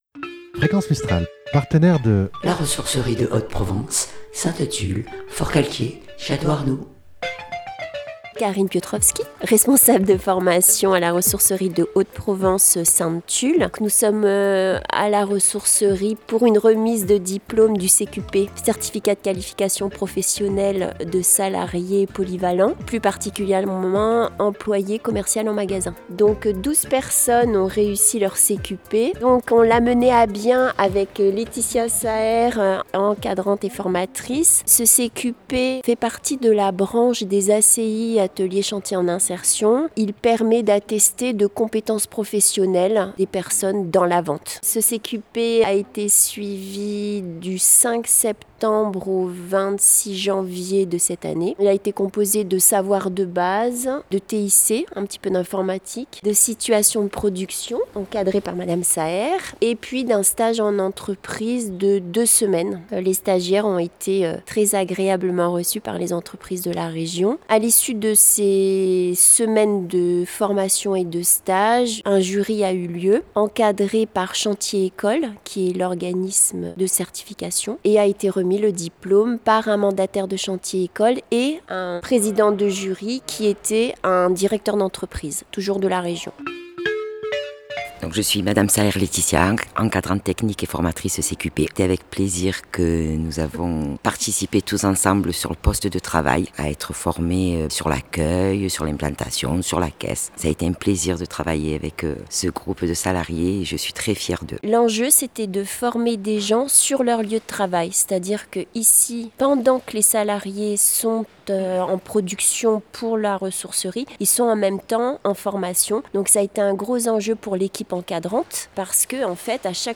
REMISE DE DIPLOME du CQP à La Ressourcerie de Haute Provence Sainte-Tulle-Forcalquier-Châteaux-Arnoux
Remise de Diplôme du Certificat de qualification Professionnelle à La RHP.